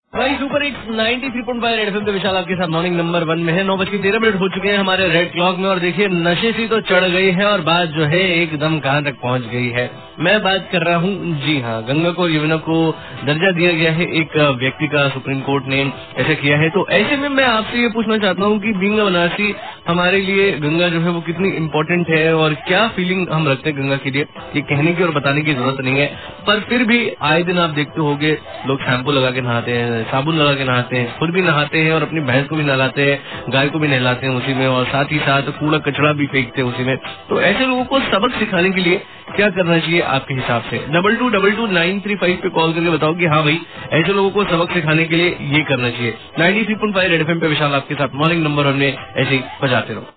rj about ganga river